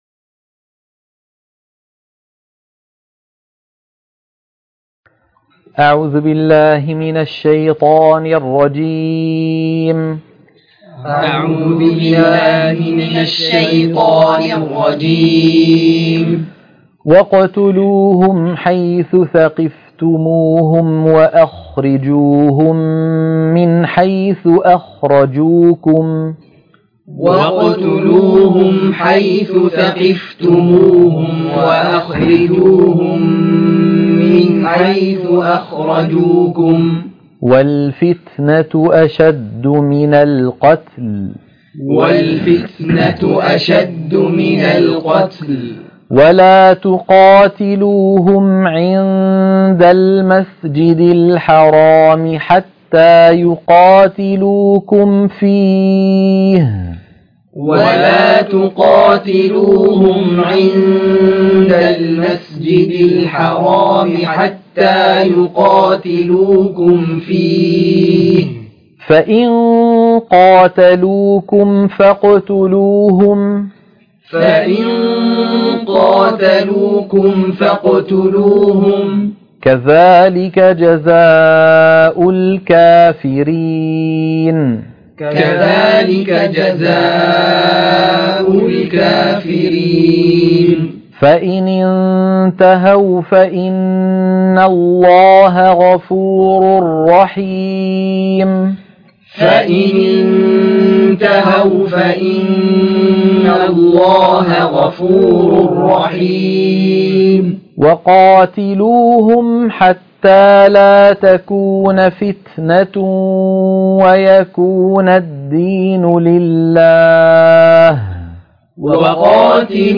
عنوان المادة تلقين سورة البقرة - الصفحة 30 _ التلاوة المنهجية